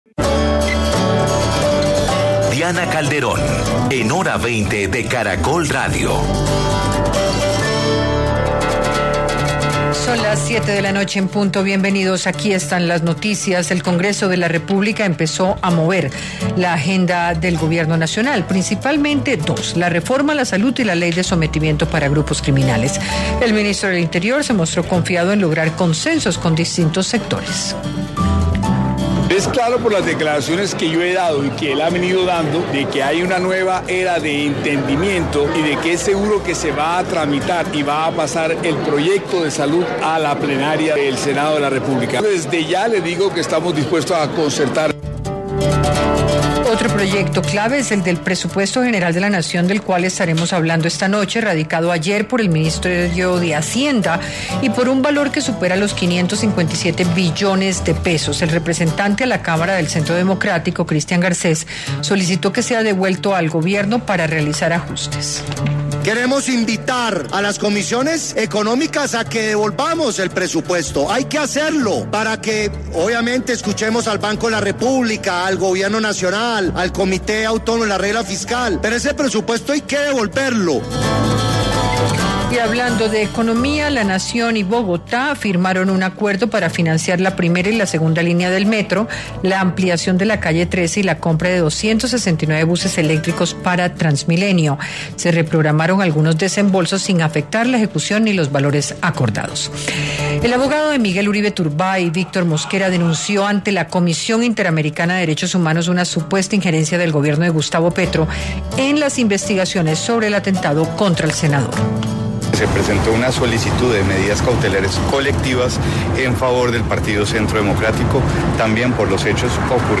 Panelistas analizaron lo que trae el proyecto de presupuesto general para el 2026, la propuesta de una nueva tributaria y lo que implica de cara a la realidad fiscal del país